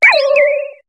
Cri de Funécire dans Pokémon HOME.